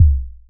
edm-perc-05.wav